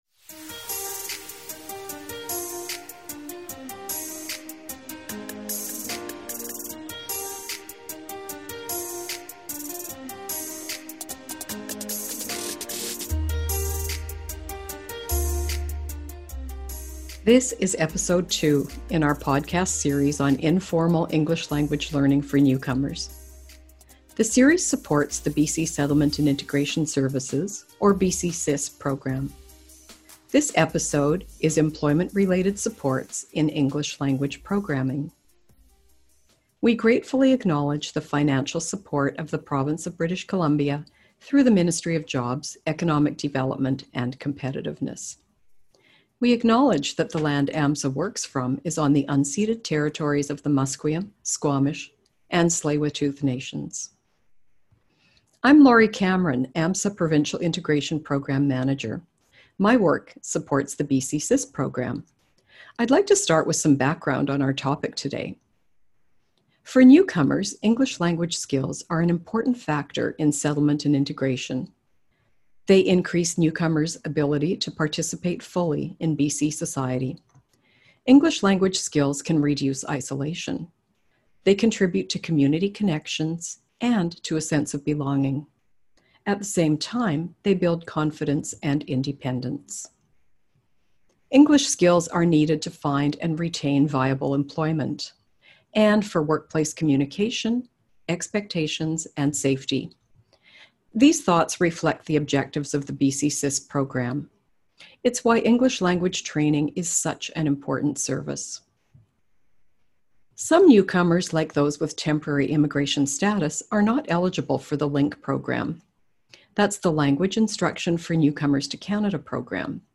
This podcast features speakers from 2 organizations that offer specialized employment-related supports in language programming. They explain how their programs, which include informal ELL instruction, can support employment readiness, improve access to employment programs, support workplace safety, and job retention.